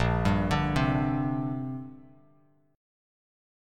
Bb6add9 chord